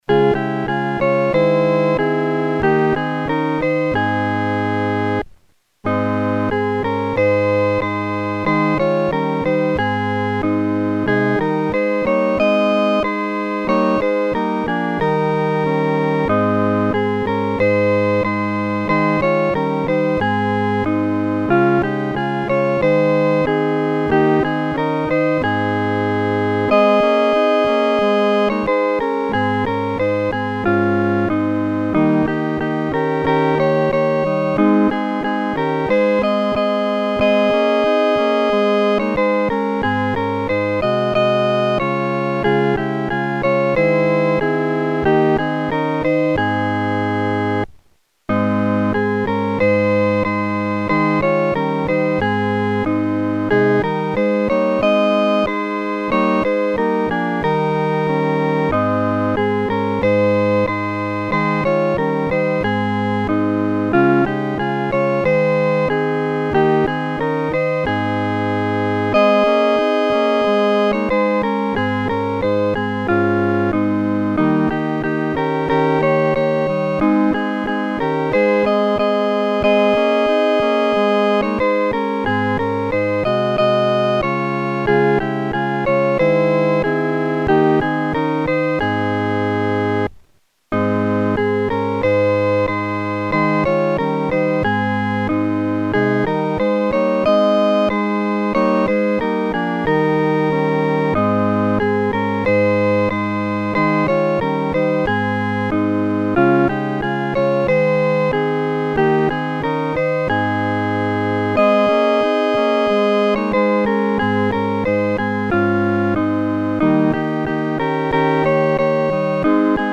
独奏（第二声） 下载